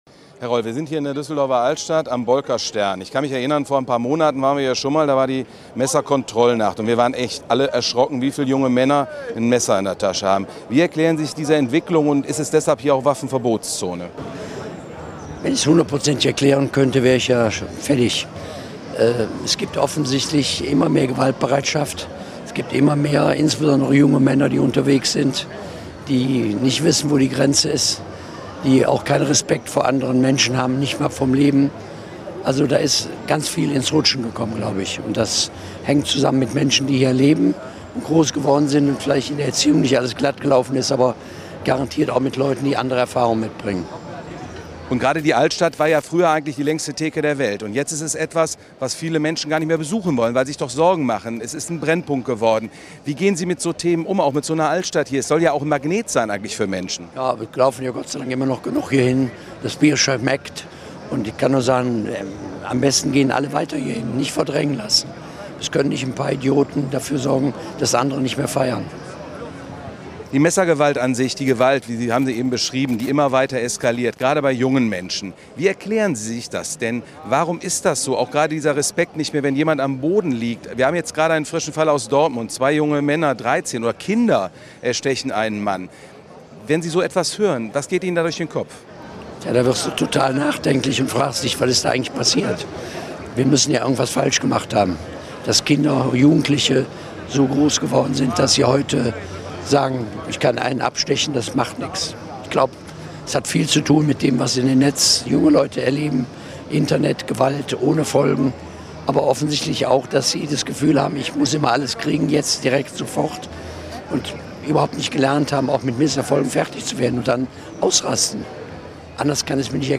BILD trifft Herbert Reul an einem Freitagabend in der Düsseldorfer Altstadt, einem Kriminalitätsbrennpunkt, der mittlerweile eine Waffenverbotszone ist – und der Minister redet Klartext.